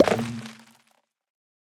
Minecraft Version Minecraft Version snapshot Latest Release | Latest Snapshot snapshot / assets / minecraft / sounds / block / beehive / enter.ogg Compare With Compare With Latest Release | Latest Snapshot